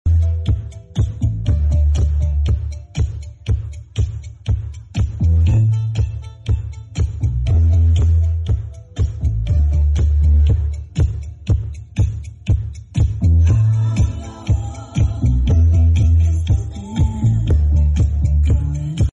SOUND CHECK